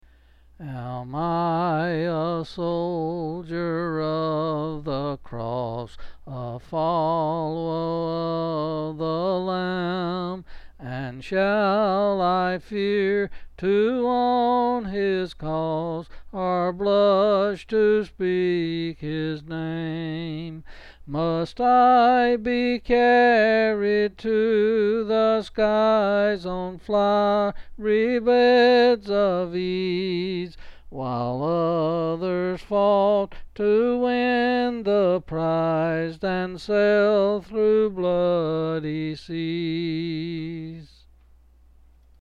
Quill Selected Hymn